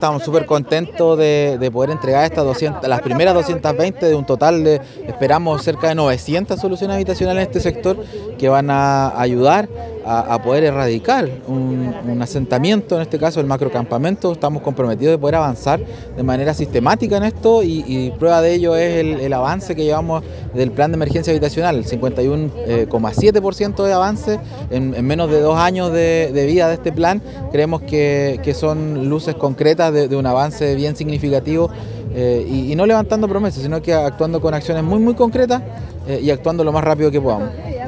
Fabian Nail, seremi de vivienda y urbanismo en Los Lagos, destacó el avance del Plan de Emergencia Habitacional y anunció que proyecta entregar casi 900 viviendas para la erradicación de los campamentos del sector.